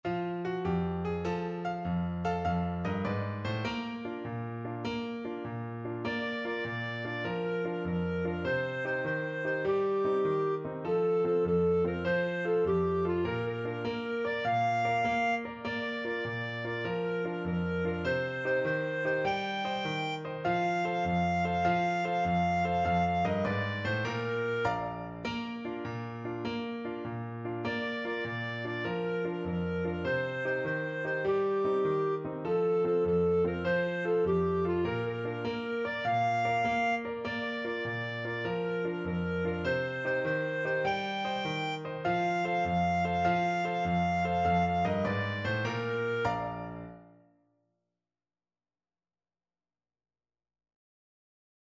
Traditional Trad. One Potato, Two Potatoes Clarinet version
Clarinet
6/8 (View more 6/8 Music)
Bb major (Sounding Pitch) C major (Clarinet in Bb) (View more Bb major Music for Clarinet )
Allegro .=c.100 (View more music marked Allegro)
Traditional (View more Traditional Clarinet Music)